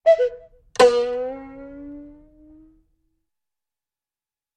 Пружина вылетела
• Категория: Настенные и напольные часы с кукушкой
• Качество: Высокое